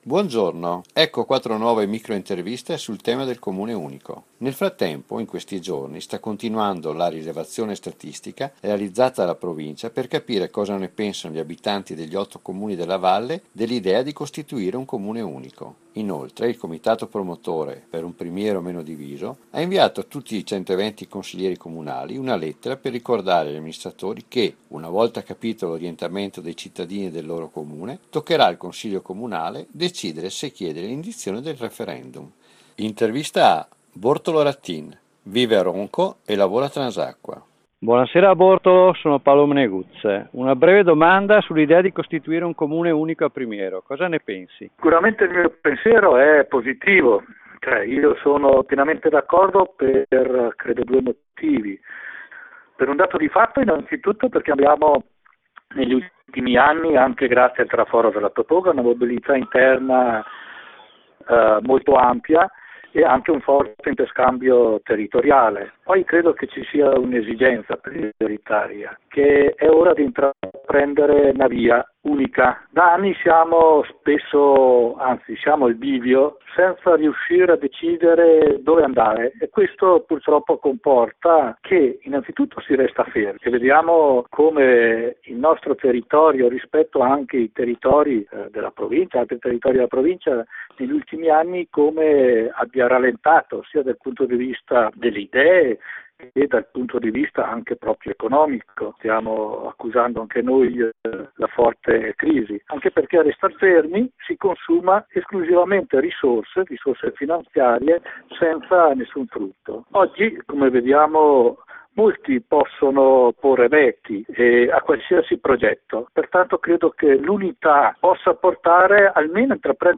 Interviste Per Un Primiero Meno Diviso